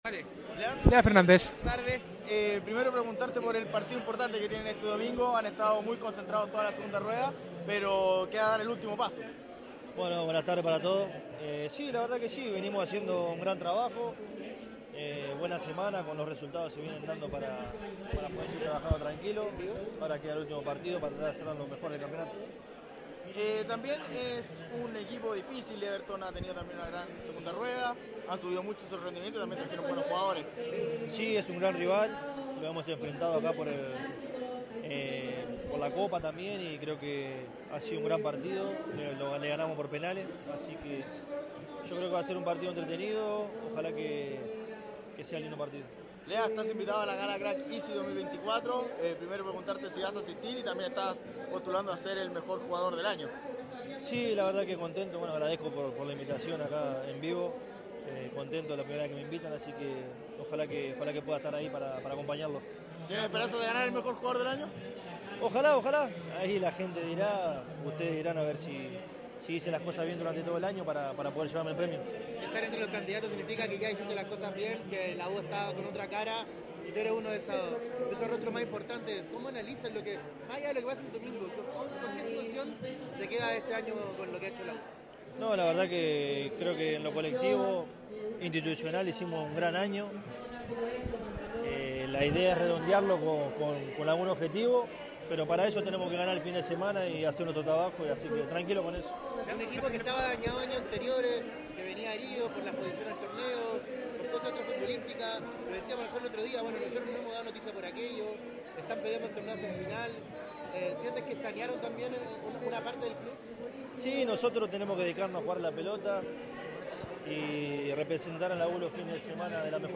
El ariete participó en el lanzamiento de una tienda de deportes, instancia donde analizó a Everton: “es un gran rival, los hemos enfrentado por la Copa (Chile) también, fue un gran partido, yo creo que va a ser un partido entretenido”.